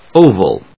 音節o・val 発音記号・読み方
/óʊv(ə)l(米国英語), ˈəʊv(ə)l(英国英語)/